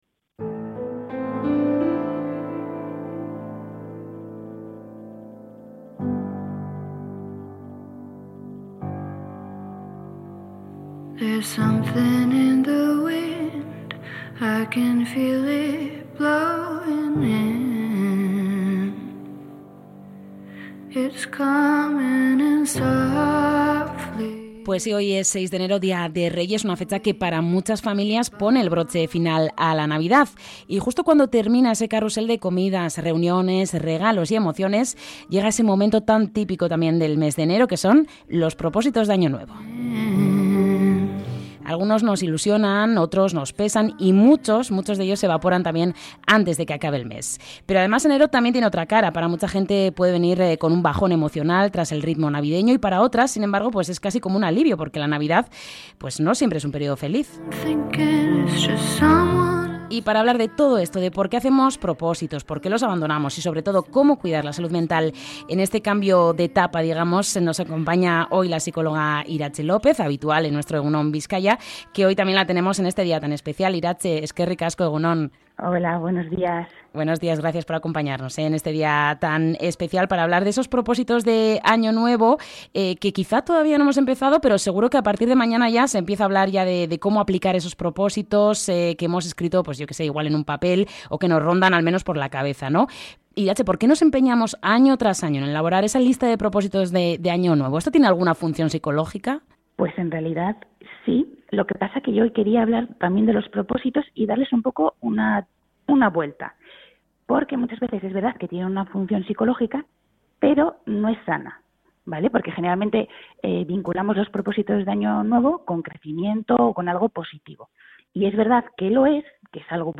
ENTREV.-PROPOSITOS-ANO-NUEVO-1.mp3